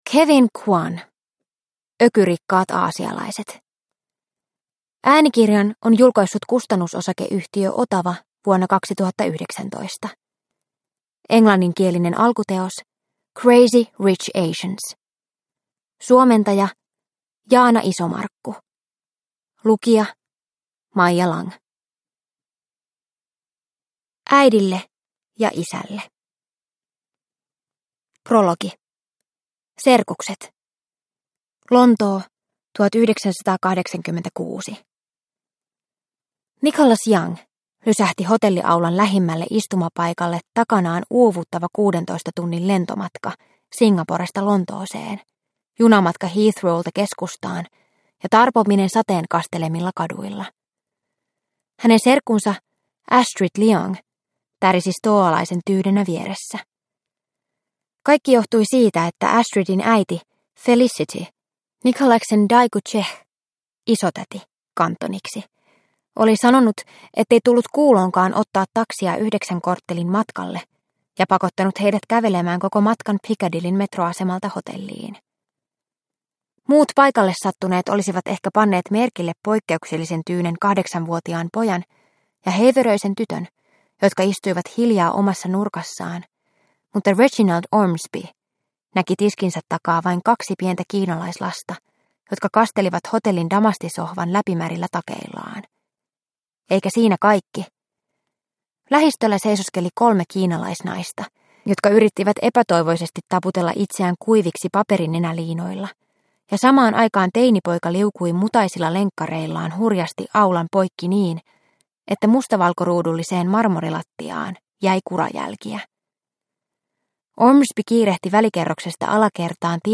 Ökyrikkaat aasialaiset – Ljudbok – Laddas ner